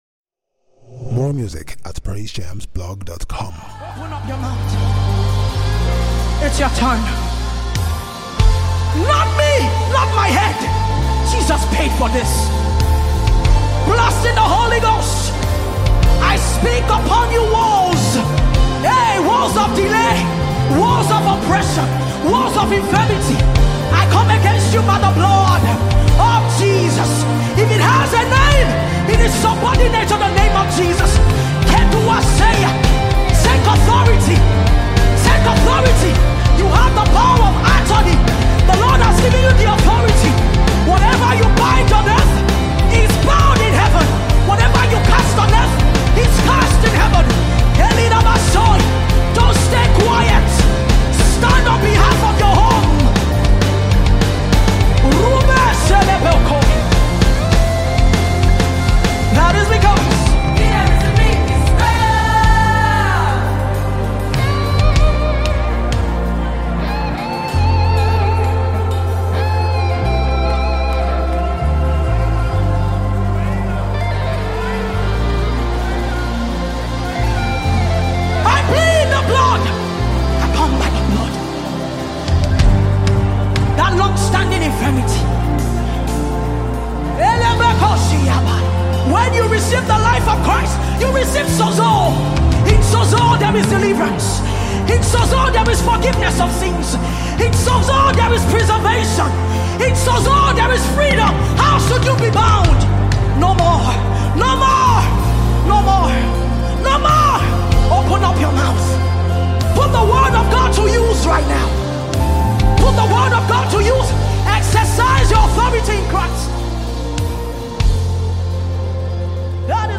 powerful, uplifting lyrics